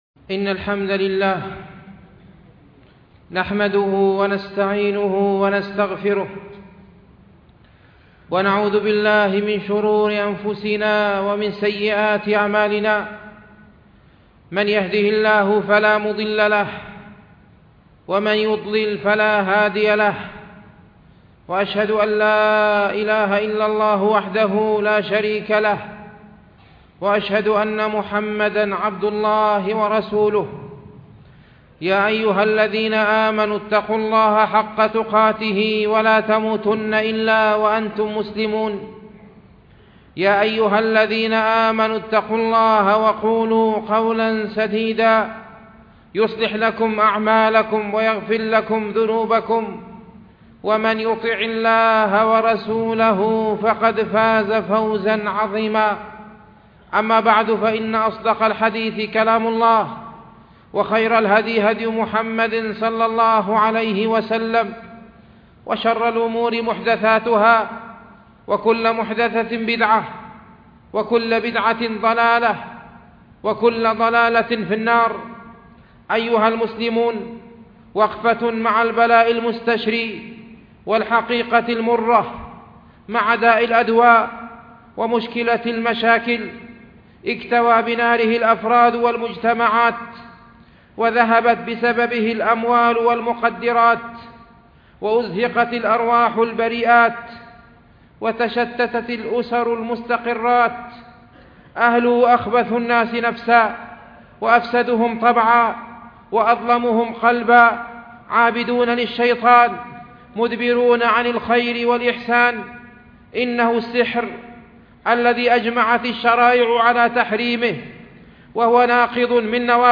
khutbah_al_saher.mp3